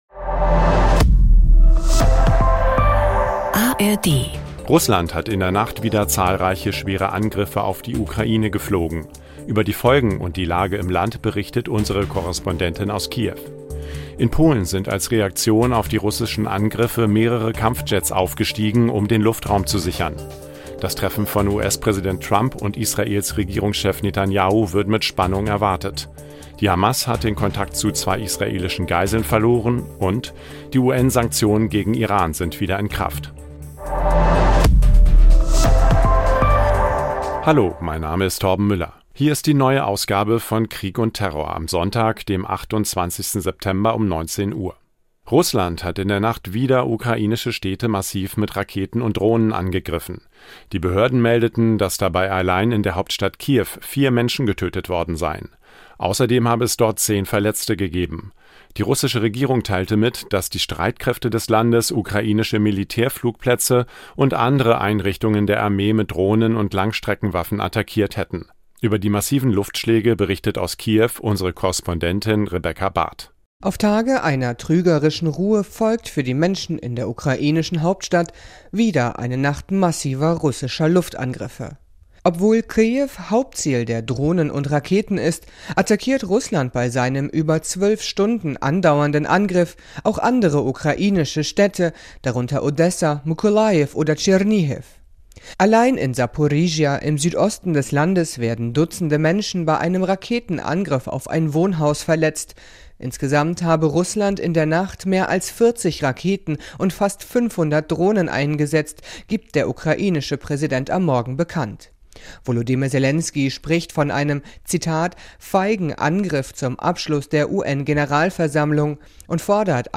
Unsere Korrespondentin berichtet aus Kiew über die Lage im Land │